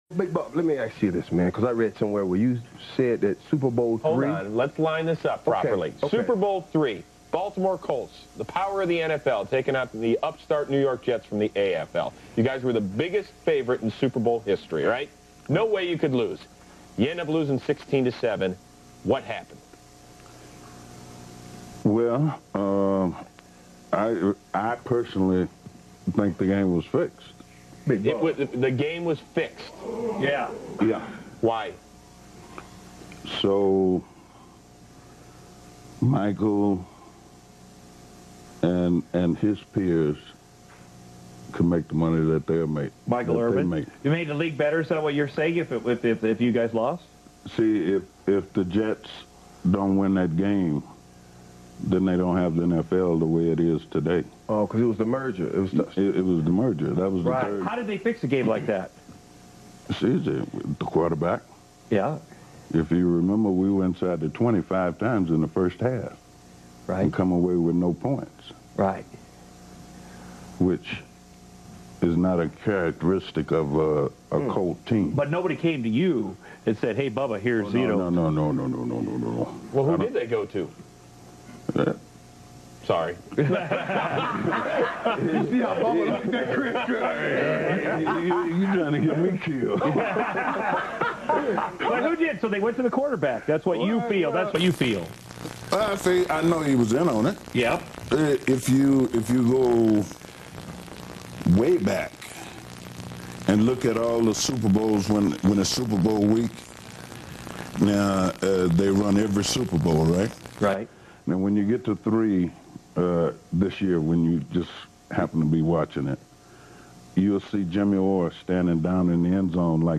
Former NFL star and actor Bubba Smith talking about how Super Bowl III was rigged